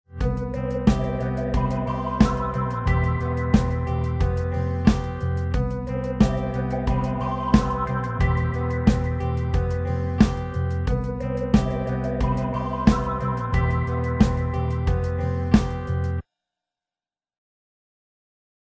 Nedan finns ljudfiler och tillhörande bilder för olika betoningar.
Trupp A Betoningar 3 (mp4)